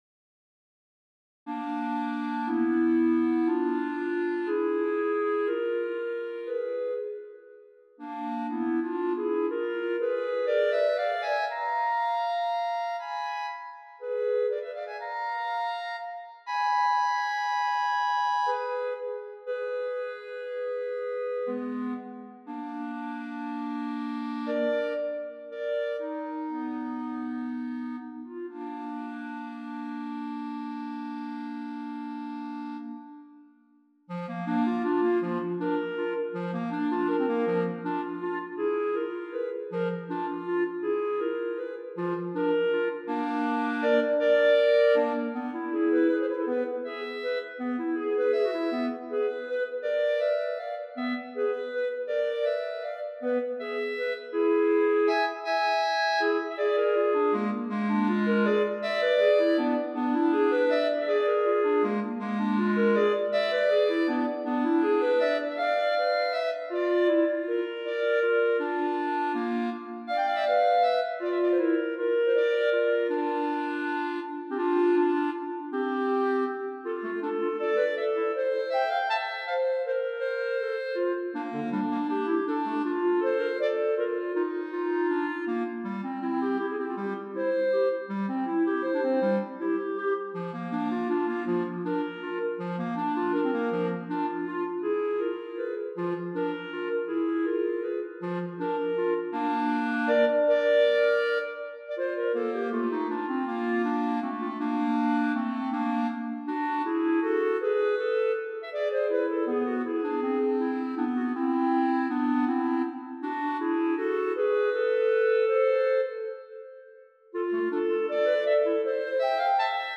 Of musical tidbits, this little duo for two players was begun with the notion of setting a tonic major in cooperation with a 'false dominant' at the augmented fourth (or diminished fifth).
Then at the presto, the opening statement  is "answered" at the region of that augmented fourth -- B flat to E. Thereafter the two players exchange roles and tonalities thereby. From presto to andante and back to presto, this little work passes themes and roles off between the two for the fun of it.
9 pages, circa 8' 45" - an MP3 demo is here: